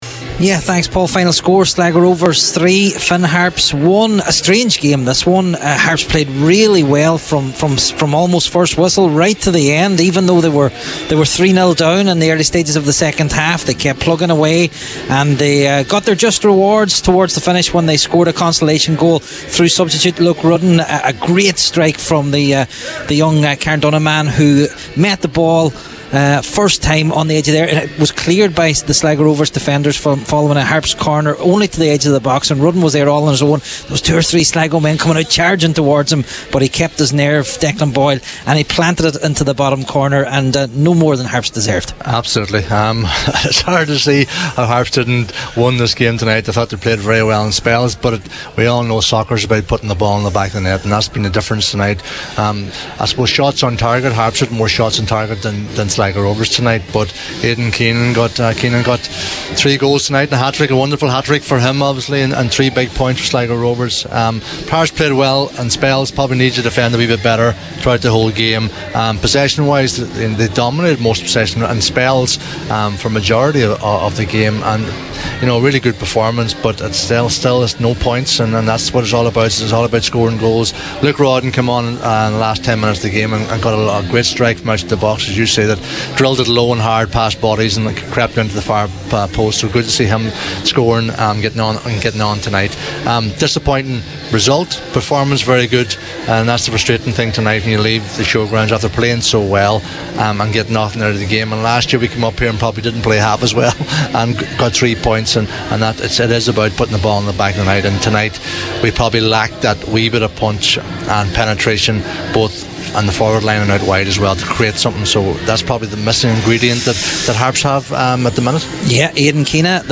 report for Highland Radio Sport at The Showgrounds in Sligo: